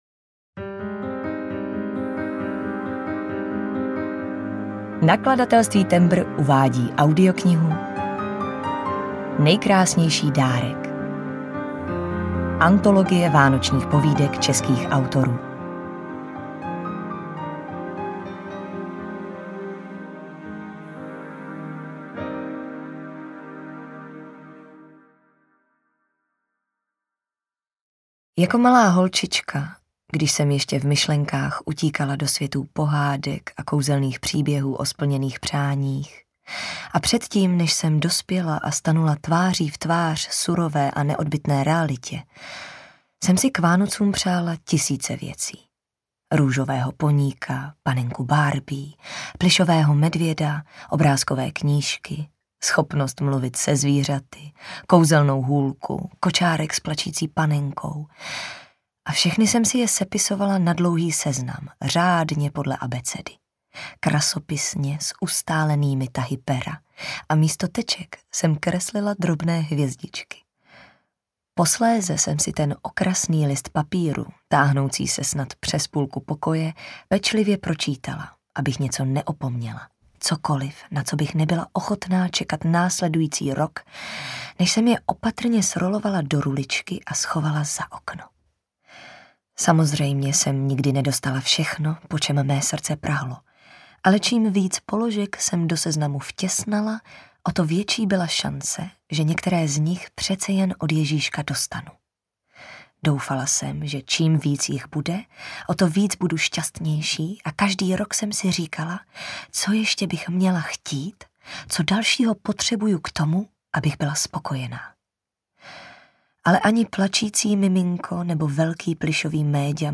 Nejkrásnější dárek audiokniha
Ukázka z knihy
nejkrasnejsi-darek-audiokniha